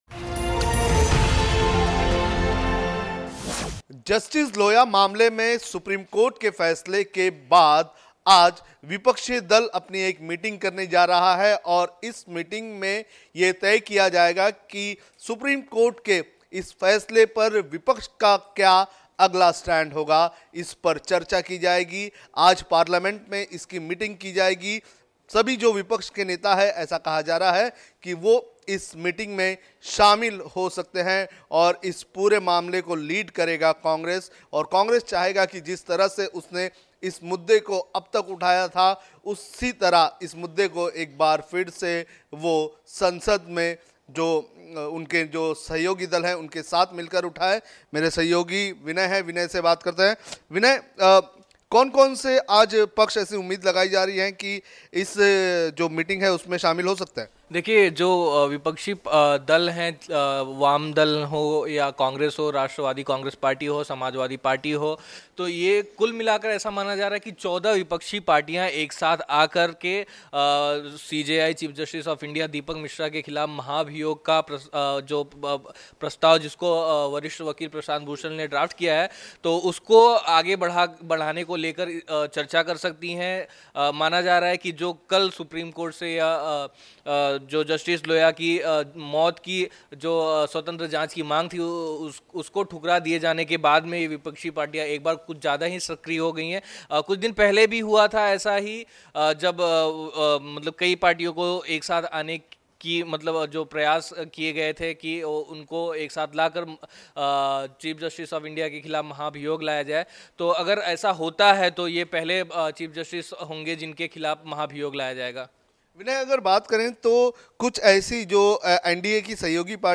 News Report / लोया मामले पर कांग्रेस का प्लान "B"